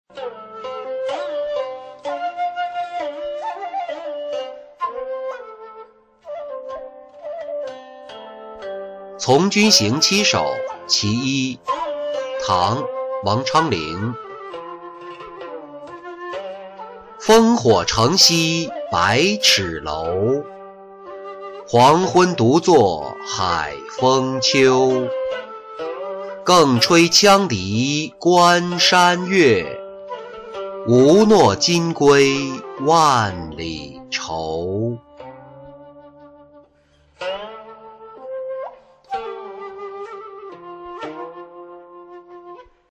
从军行七首·其一-音频朗读